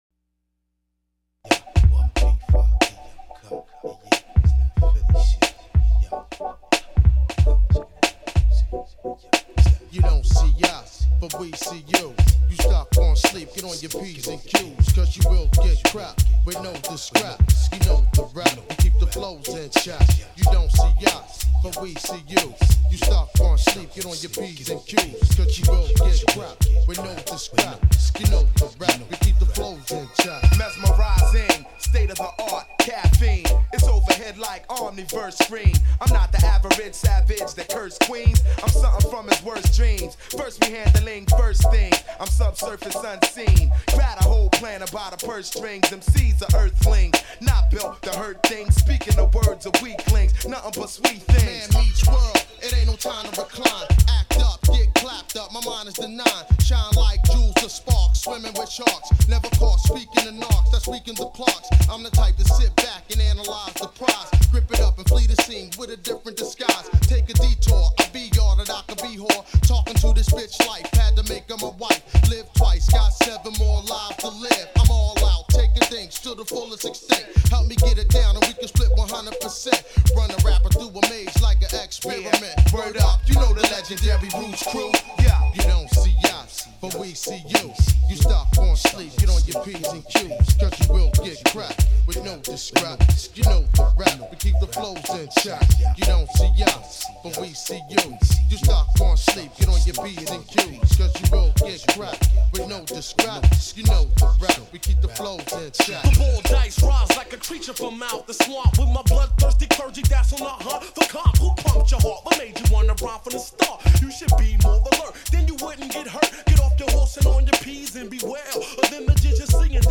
Very dope and electronic sounds….